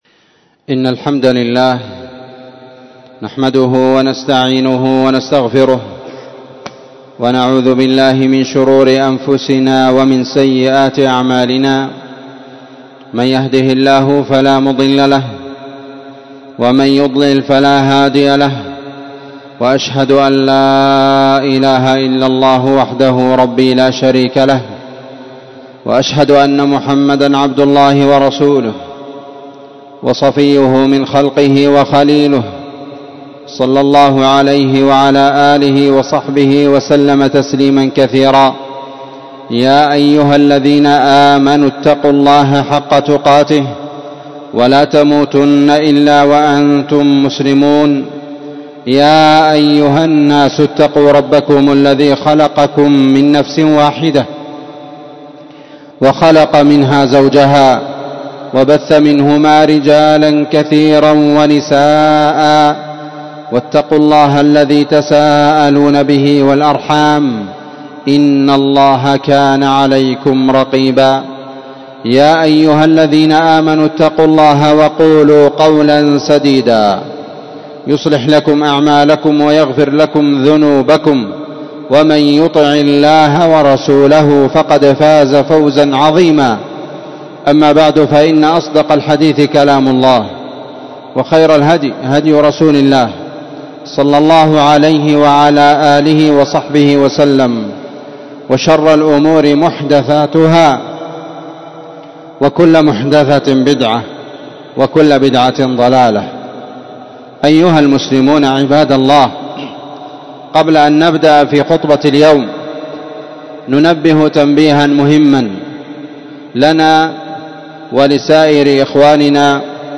عنوان خطر التحايل على المنهيات لارتكابها 22رجب.mp3 تاريخ النشر 2024-02-03 وصف خطبة جمعة قيمة جدًا بعنوان: خطر التحايل على المنهيات لارتكابها 22رجب.
مسجد المجاهد- النسيرية- تعز